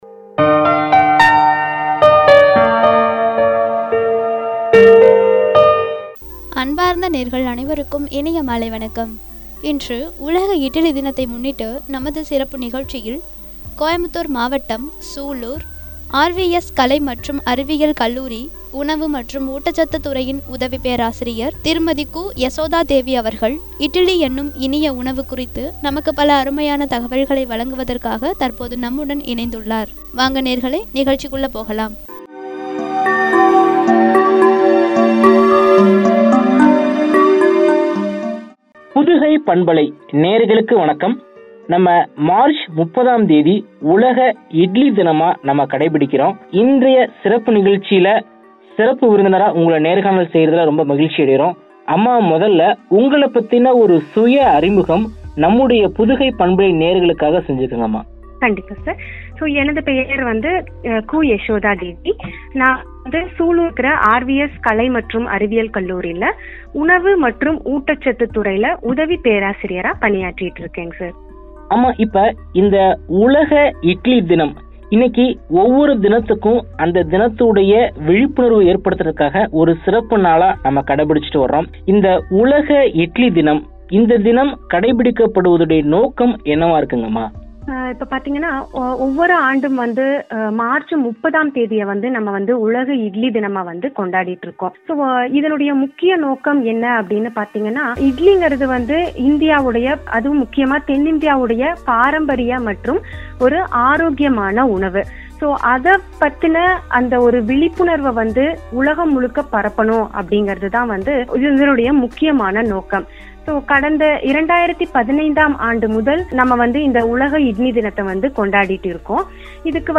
“இட்லி என்னும் இனிய உணவு” என்ற தலைப்பில் வழங்கிய உரையாடல்.